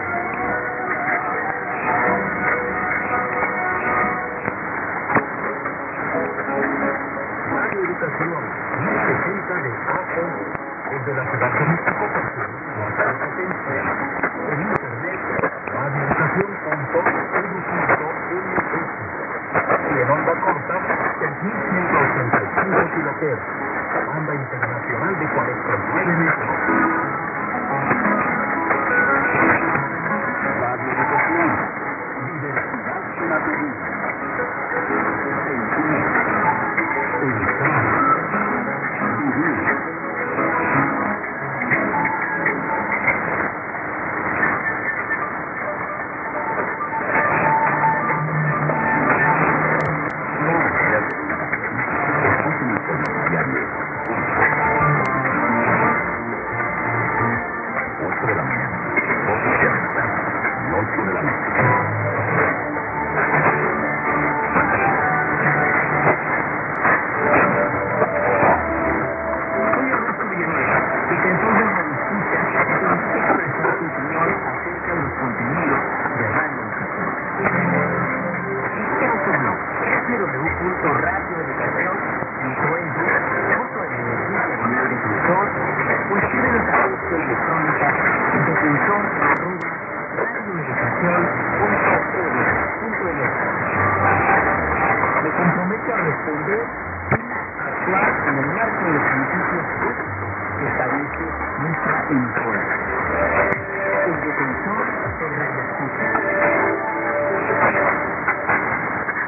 ・このＨＰに載ってい音声(ＩＳとＩＤ等)は、当家(POST No. 488-xxxx)愛知県尾張旭市で受信した物です。
ST: signature tune/jingle